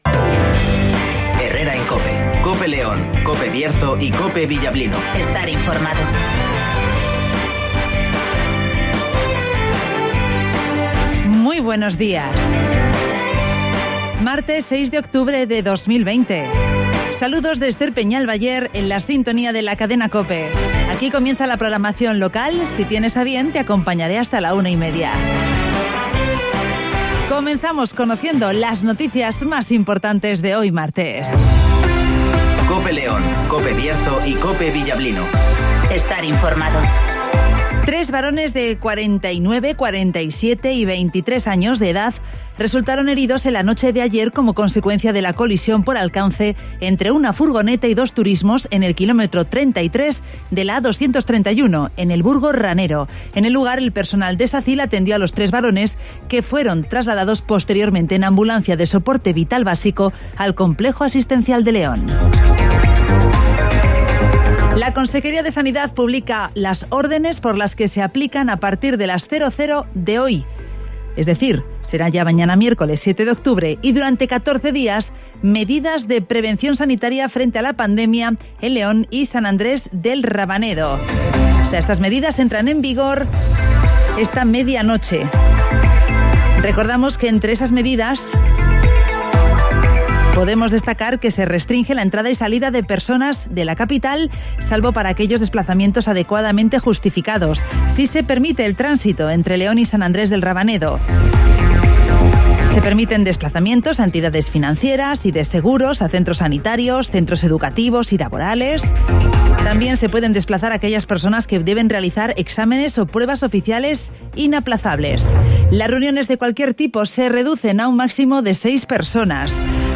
Avance informativo, El Tiempo (Neucasión) y Agenda Cultural (Carnicerias Lorpy)